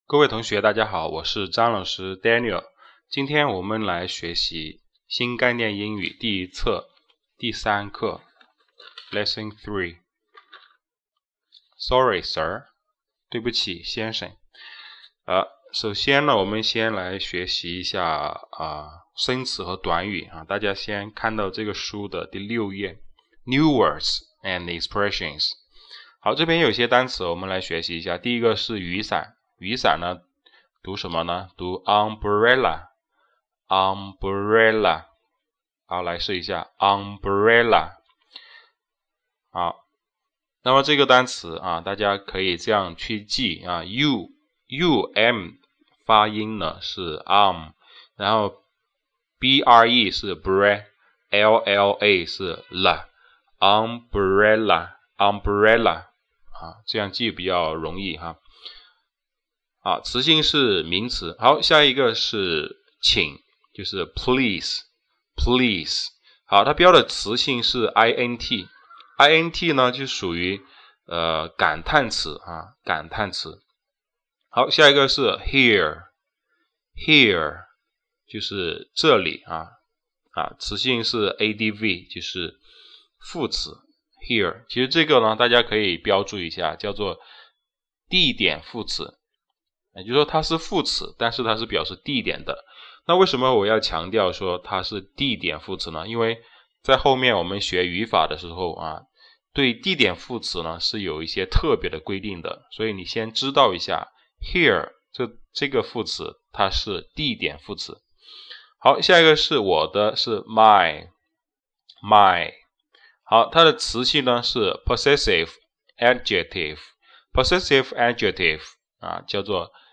本套课程包含《新概念英语第一册》整本书144课，每一课（包括偶数课）的详细讲解，其中包括单词详解和课文详解，课程为音频录播格式